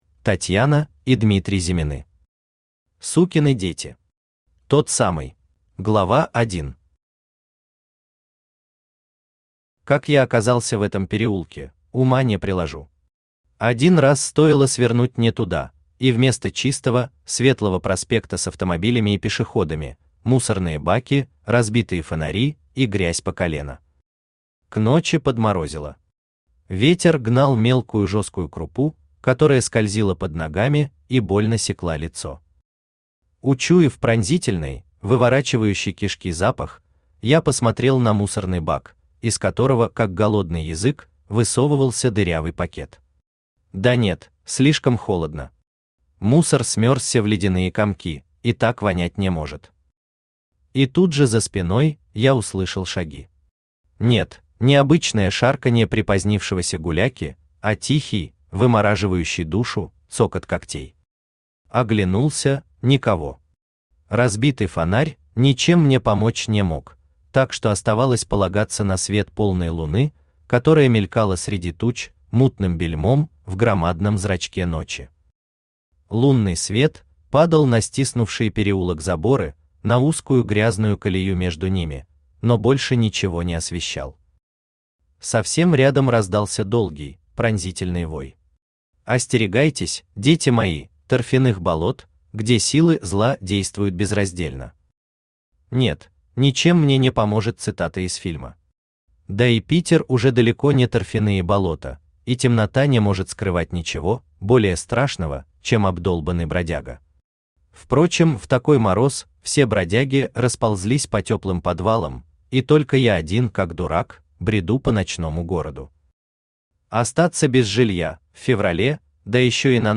Аудиокнига Сукины дети. Тот самый | Библиотека аудиокниг
Тот самый Автор Татьяна и Дмитрий Зимины Читает аудиокнигу Авточтец ЛитРес.